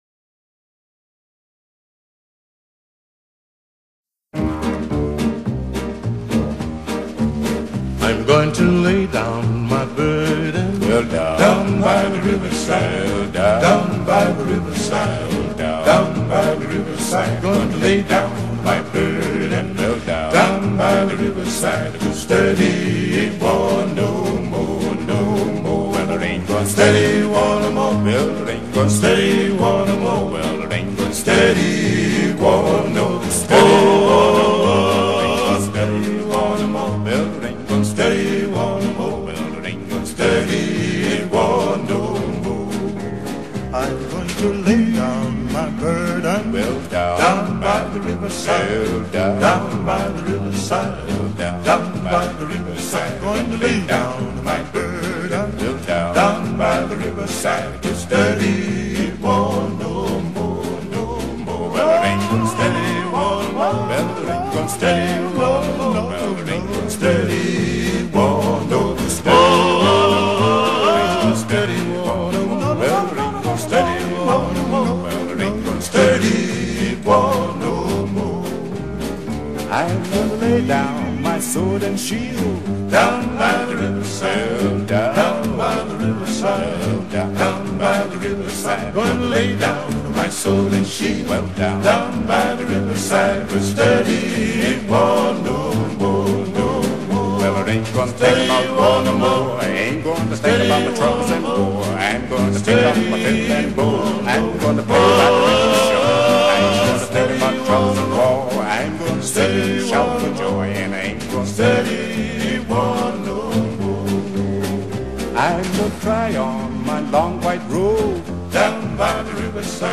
Chœur d’hommes fondé en 1860
Negro spiritual traditionnel
Interprété par le Chœur du Léman en : 2014, 2022
H10033-Live.mp3